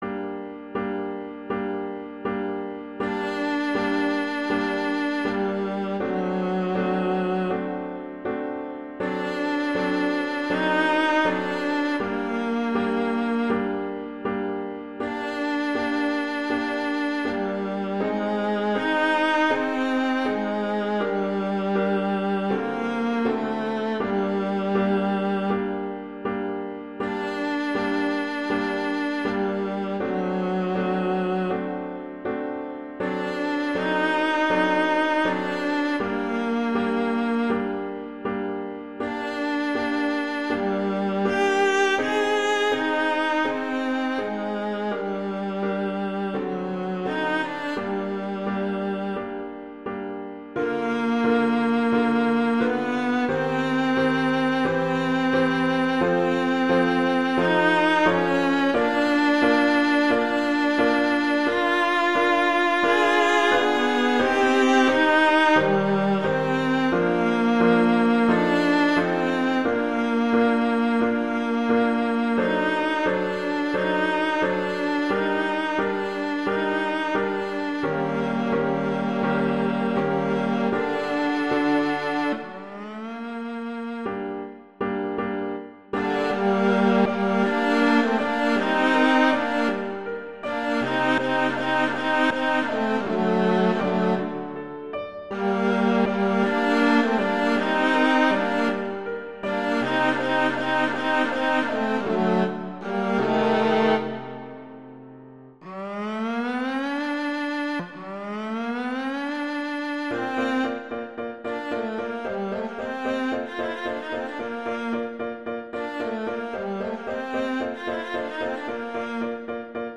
classical
Bb major
♩=80 BPM